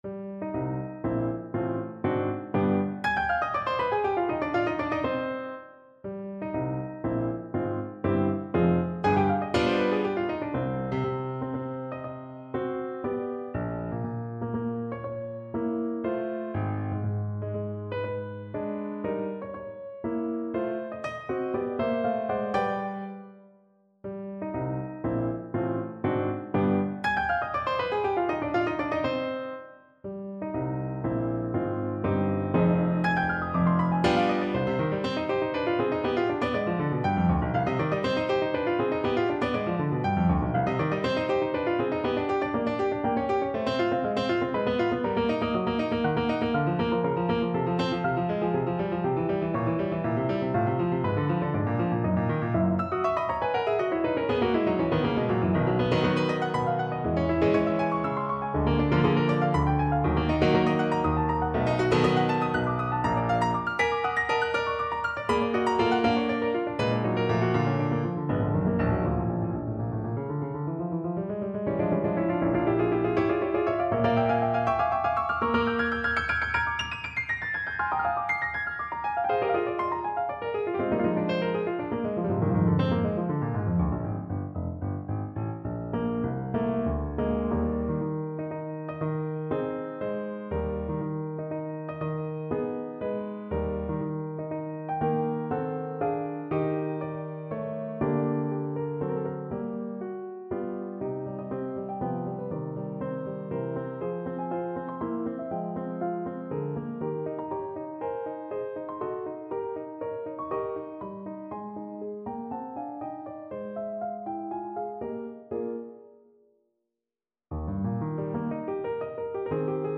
No parts available for this pieces as it is for solo piano.
Eb major (Sounding Pitch) (View more Eb major Music for Piano )
3/4 (View more 3/4 Music)
Allegro (View more music marked Allegro)
Piano  (View more Advanced Piano Music)
Classical (View more Classical Piano Music)